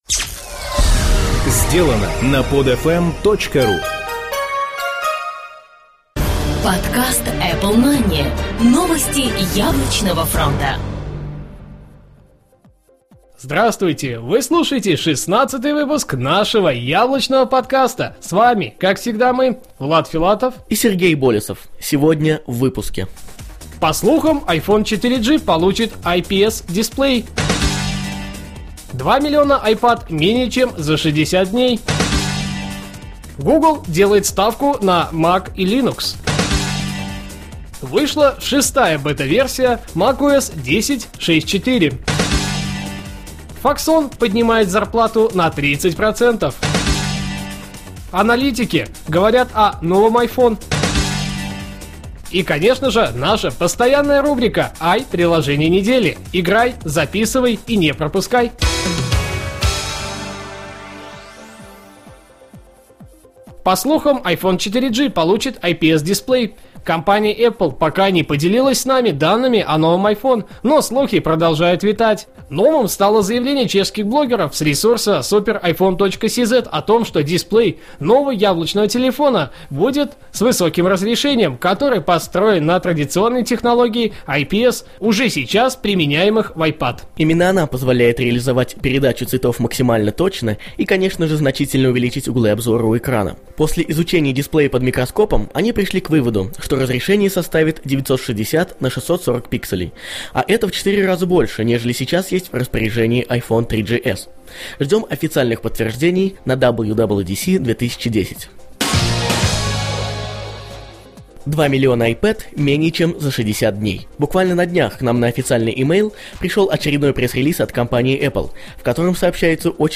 Жанр: новостной Apple-podcast
Битрейт аудио: 80-96, stereo